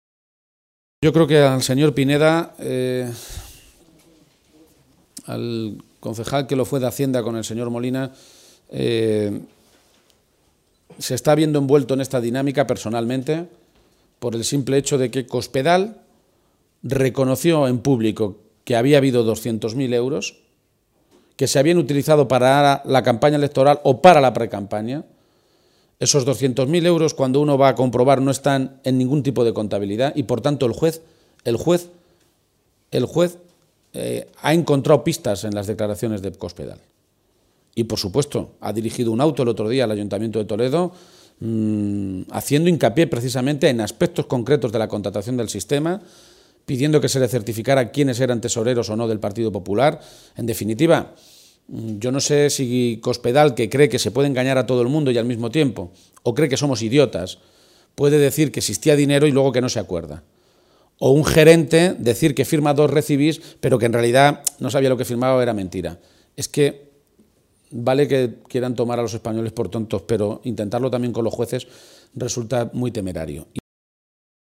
Momento de la intervención de García-Page